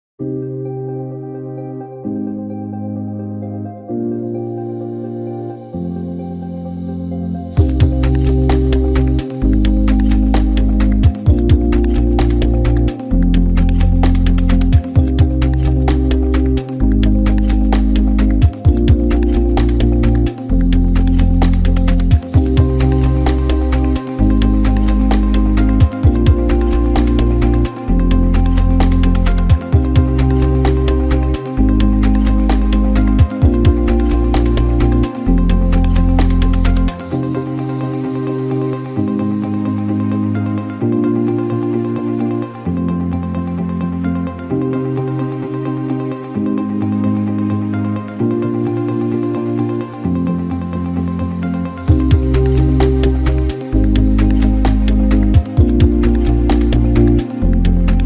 modern and futuristic electronic music